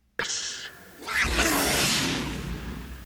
Ktriss-Snarl.ogg